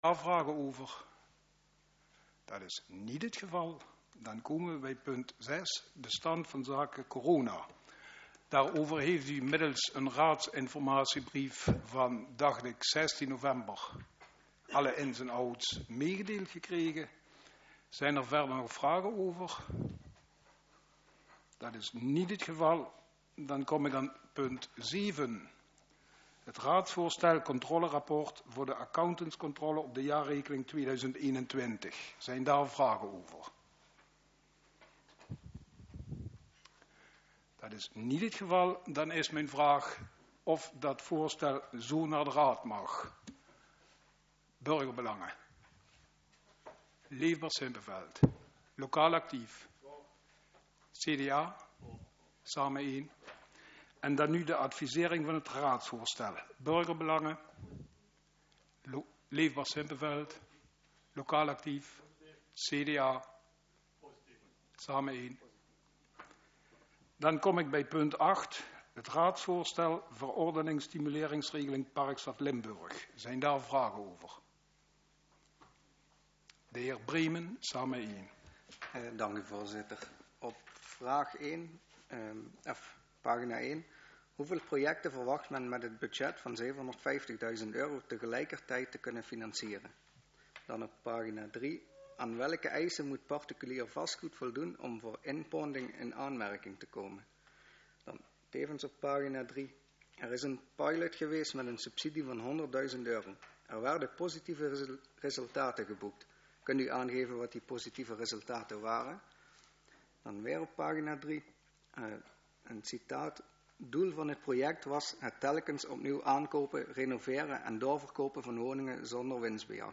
Door een technische storing zijn er geen geluidsopnames van de agendapunten 1 t/m 5.
Locatie Raadszaal Voorzitter Dhr.